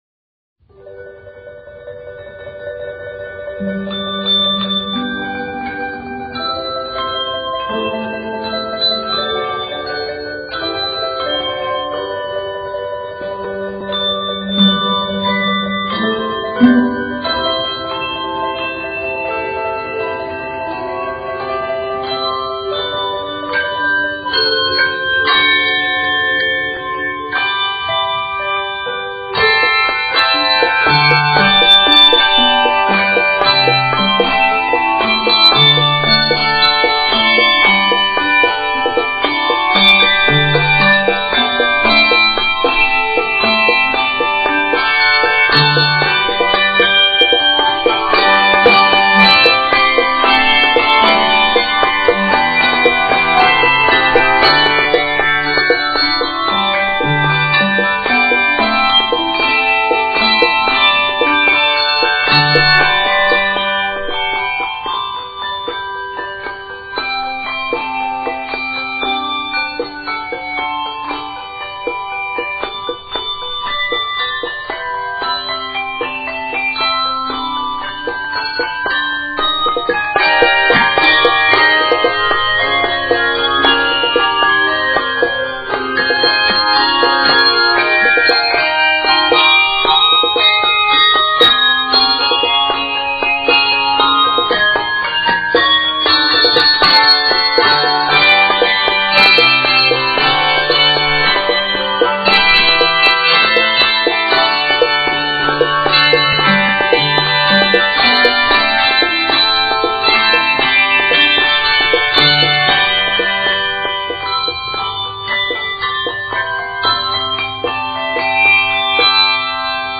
The Caribbean song most noted as a foil for the text